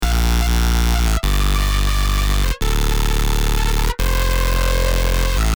❇ Ready for BASS HOUSE, TRAP, UKG, DRUM & BASS, DUBSTEP and MORE!
TN - Cooked or Not [Dmin] 174BPM
TN-Cooked-or-Not-Dmin-174BPM.mp3